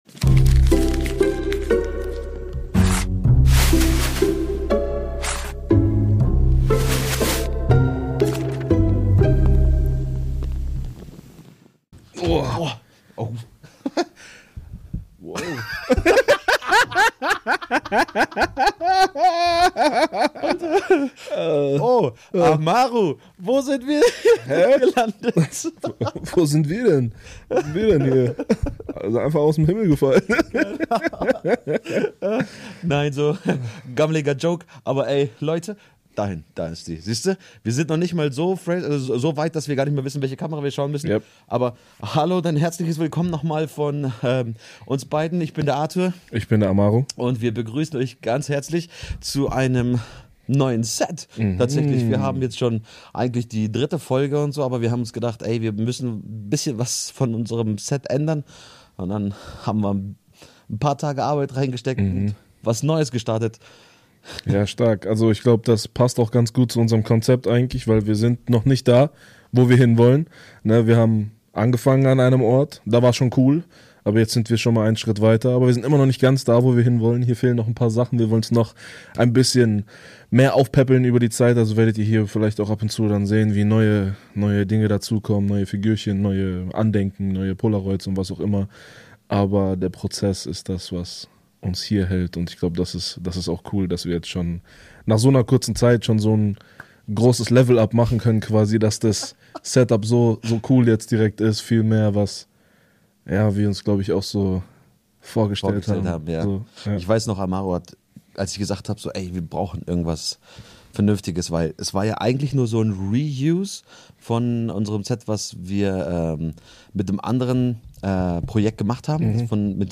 In dieser ersten Folge geht’s darum, wie man in einer Welt voller Trends, Likes und Meinungen seinen Glauben authentisch leben kann – mit Freude, Tiefe und einem klaren Blick fürs Wesentliche. Zwei Kumpels, ehrliche Gespräche, echte Fragen – und eine Menge Inspiration fürs Leben.